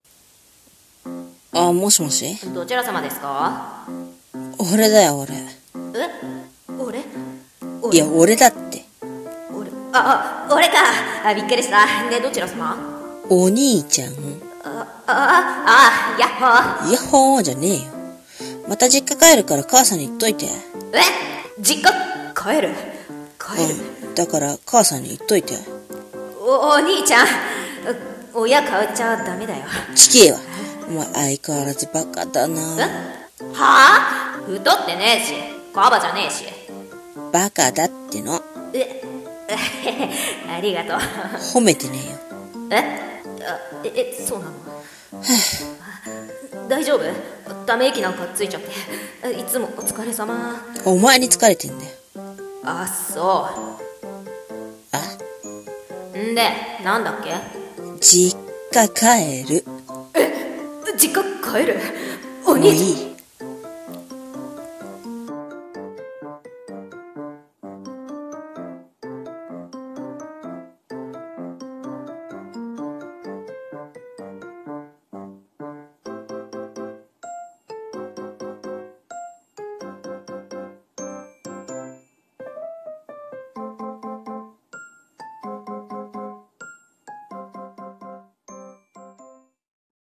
声劇台本 「弟と兄の日常電話」